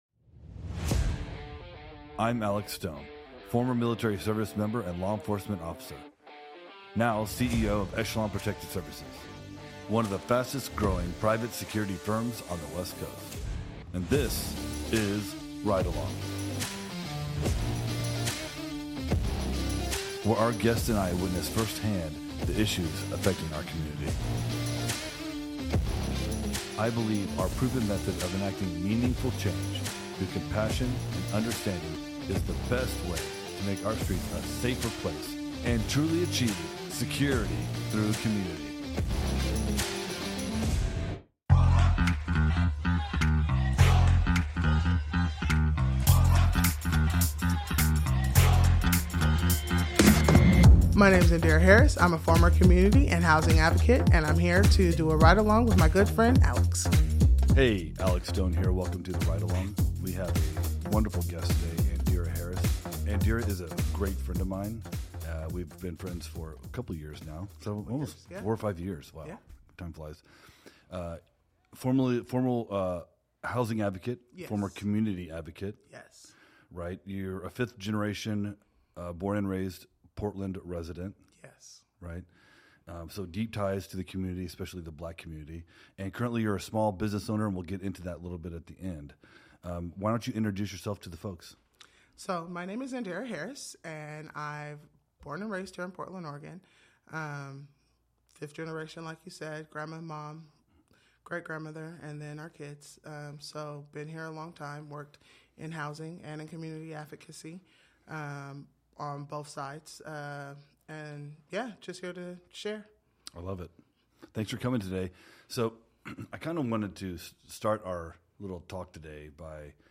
From building trust in diverse neighborhoods to navigating sensitive community incidents, they share insights on fostering compassion and understanding for meaningful change. Explore the intersections of law enforcement, community activism, and the pursuit of safer streets in this enlightening conversation.